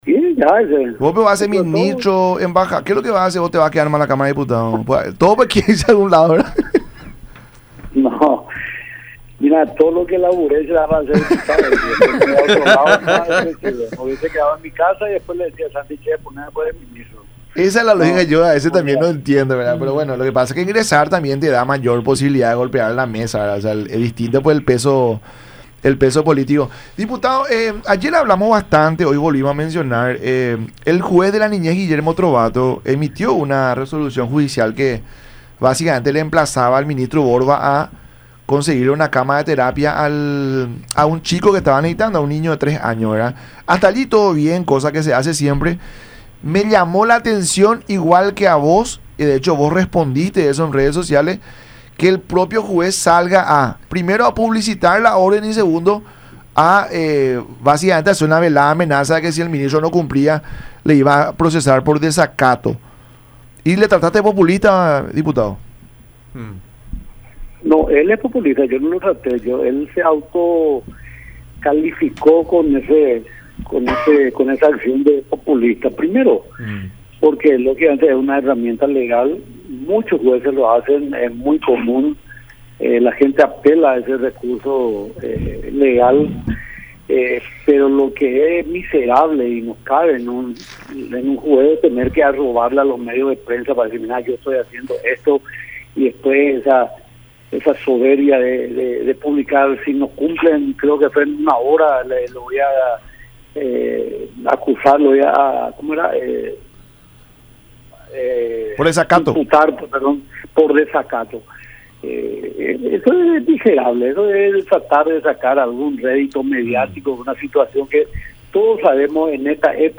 Walter Harms, diputado de ANR.
Yo no lo conozco a este señor juez, pero sacó su hilacha de populista”, aseveró Harms en diálogo con La Mañana De Unión por Unión TV y radio La Unión, en relación a la amenaza de serle abierto un proceso penal por desacato contra el ministro Borba por incumplir la orden judicial.